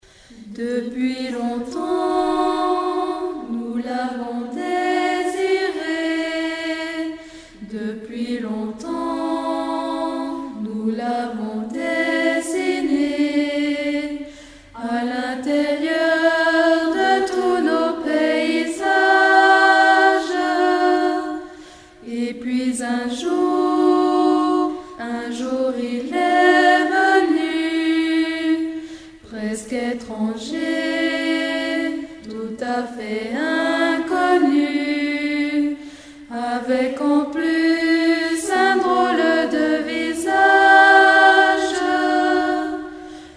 1993 - 1994 - Choeur d'enfants La Voix du GiblouxChoeur d'enfants La Voix du Gibloux
Dans ce spectacle 2 petits princes se partageaient la vedette, ceci pour faciliter la mémorisation du texte de nos pitchous d’école enfantine.